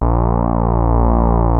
OSCAR A#1 5.wav